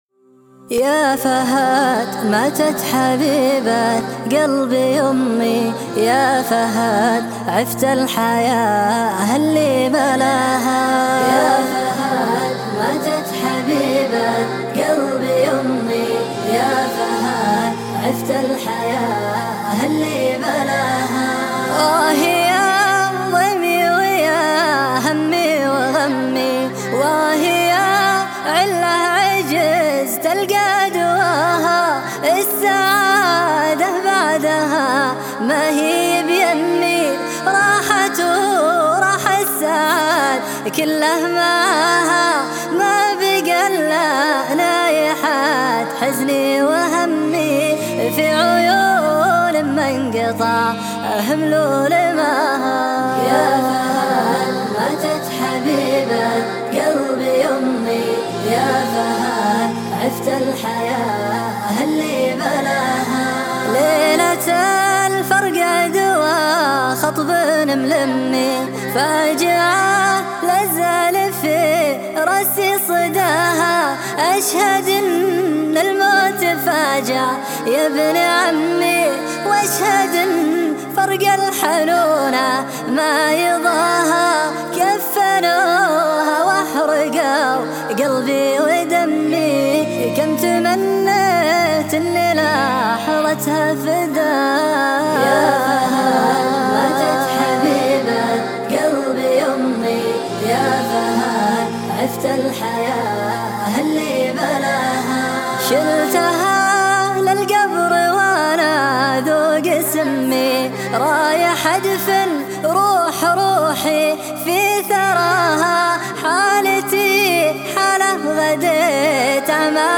الشيله